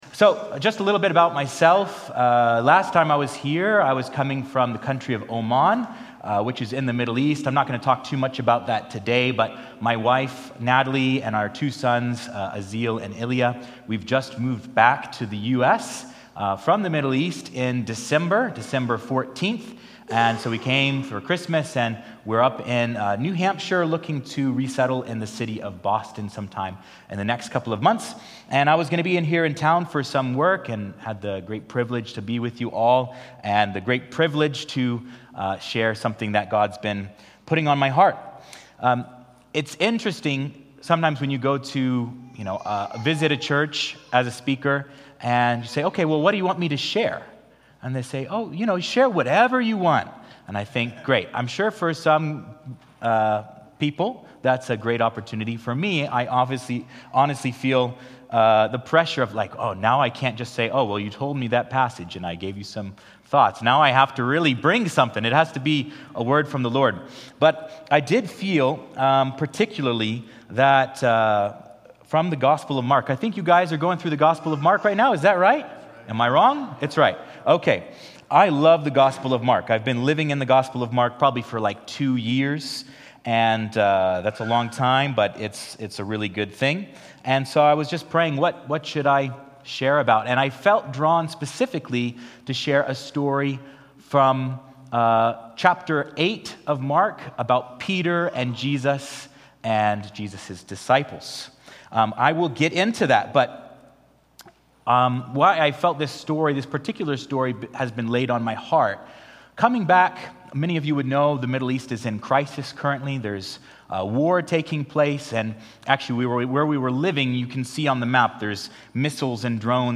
A message from the series "Standalone."